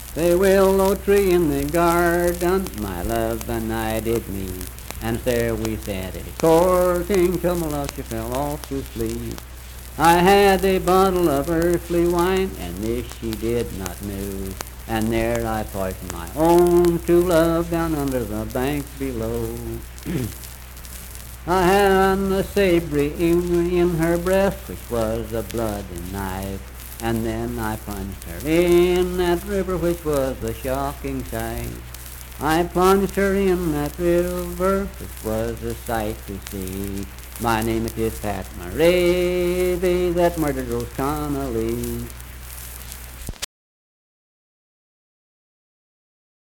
Unaccompanied vocal music performance
Verse-refrain 4(4).
Voice (sung)